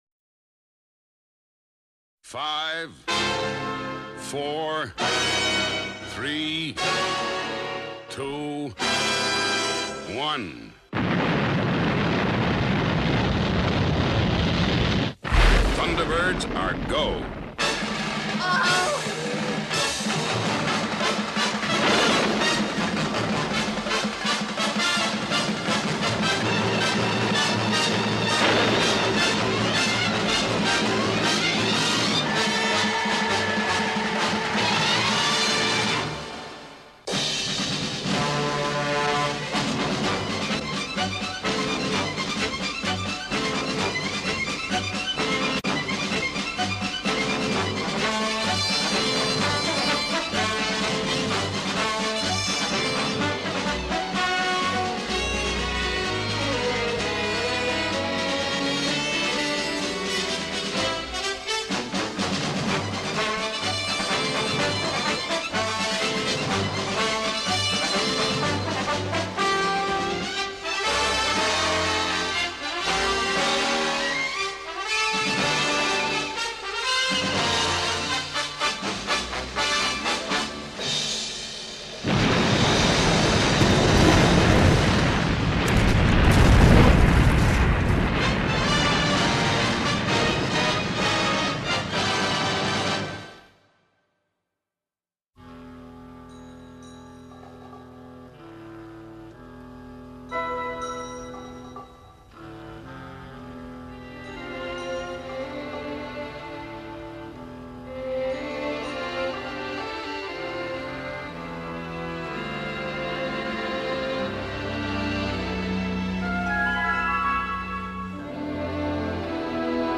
Enjoy. this is part of my christmas nostalgia treat to you all. some may need volume increase many are from VHS to AVI so what you get is what you get.